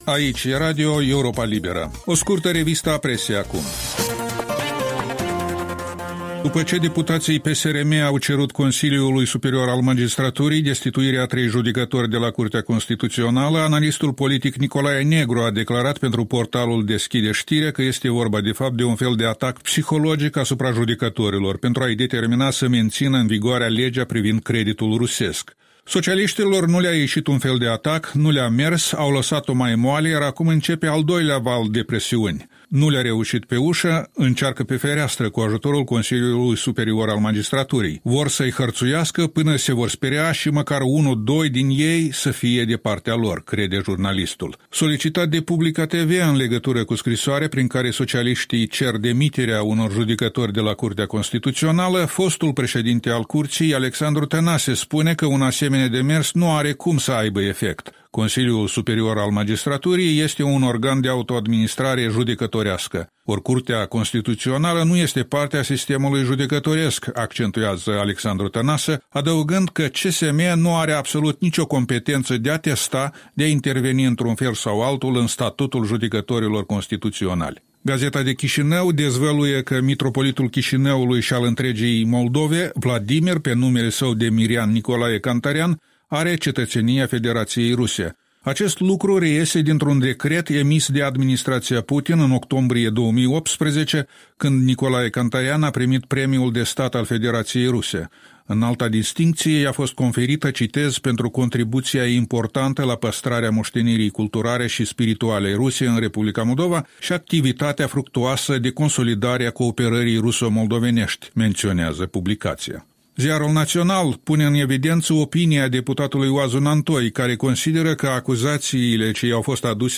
Revista presei matinale la Radio Europa Liberă.